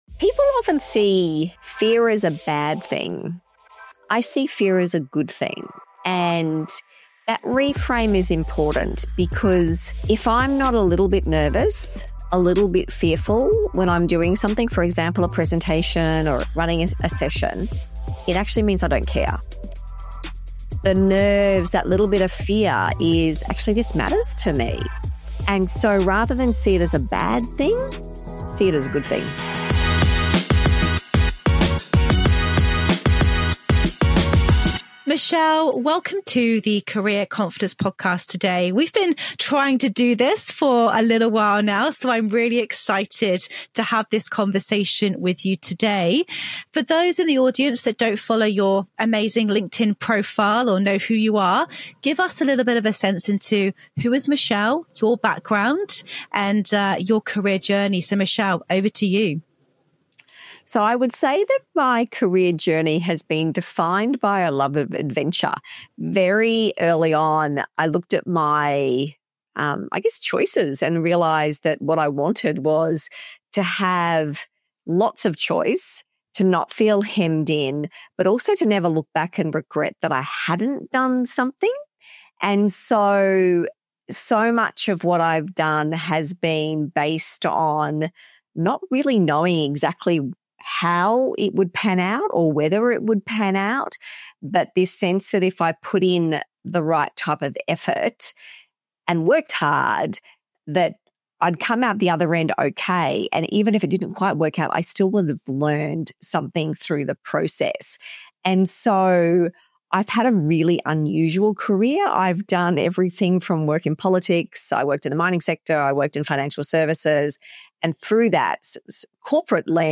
Are you afraid of taking the next step in your career? This conversation will completely change how you think about fear.